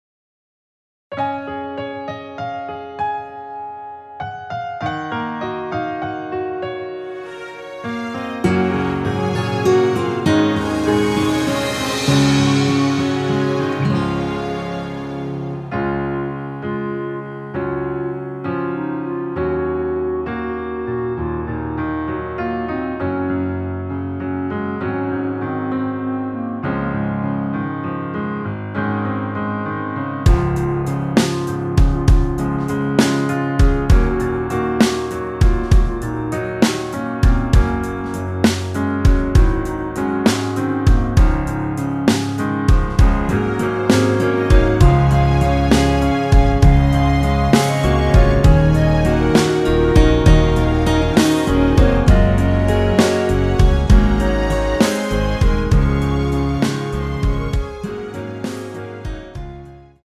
원키에서(-7)내린 멜로디 포함된 짧은 편곡 MR입니다.
Db
앞부분30초, 뒷부분30초씩 편집해서 올려 드리고 있습니다.
중간에 음이 끈어지고 다시 나오는 이유는